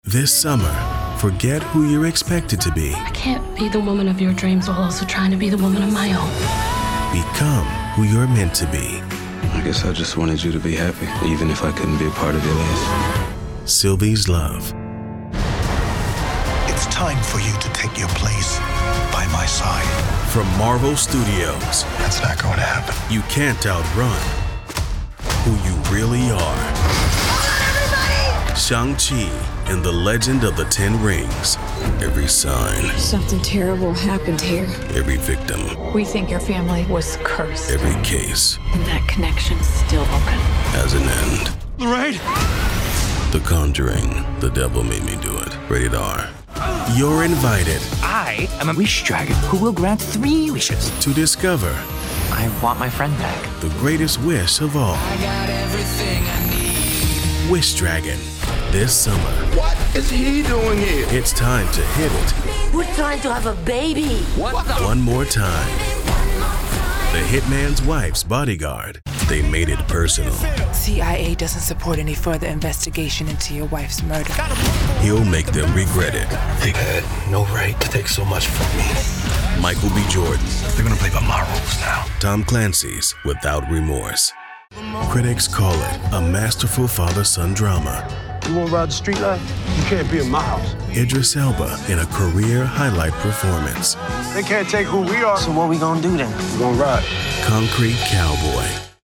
Narration Demo
vo_demo.mp3